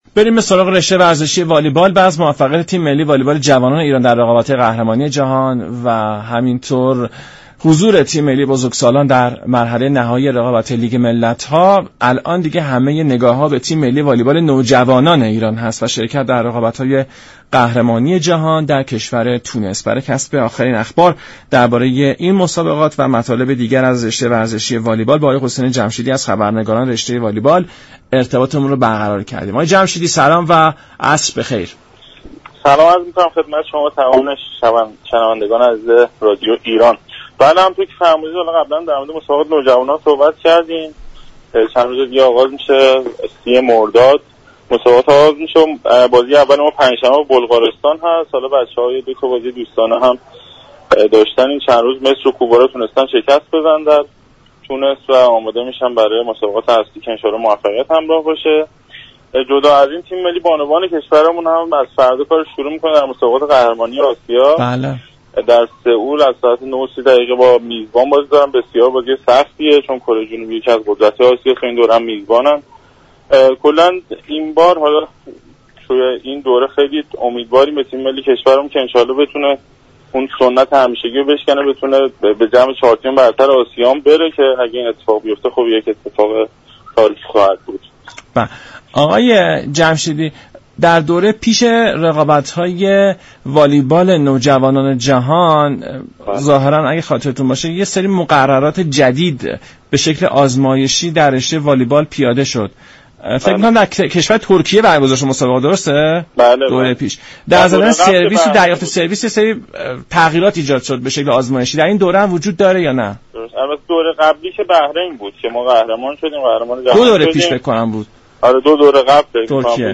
برنامه ورزش ایران شنبه تا چهارشنبه هر هفته ساعت 17:10 از رادیو ایران پخش می شود.